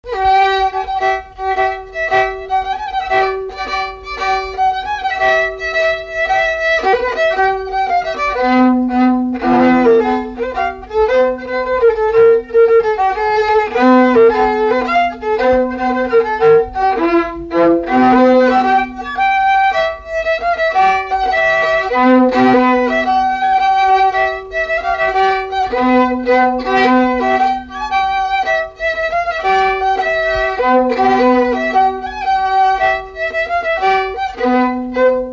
3ème figure du quadrille vendéen
Résumé instrumental
danse : quadrille : poule
Pièce musicale inédite